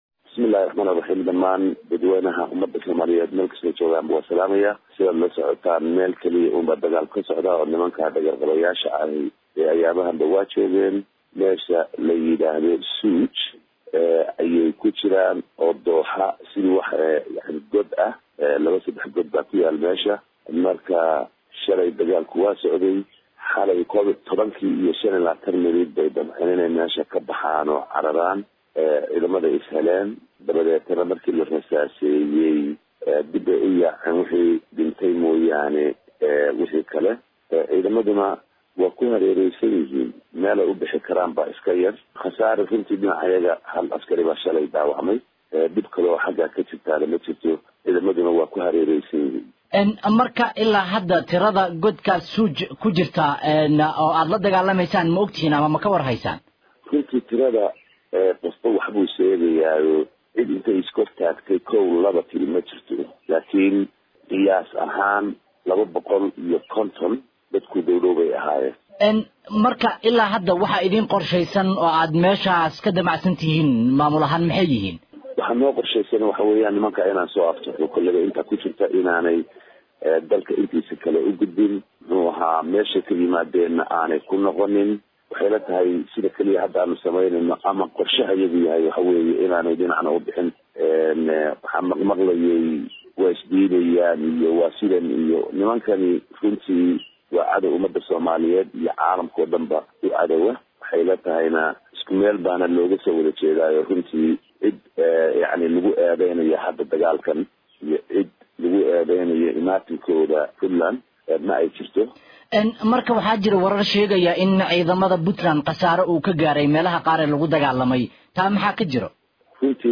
DHAGEYSO WAREYSIGA MADAXWEYNE KU XIGEENKA PUNTLAND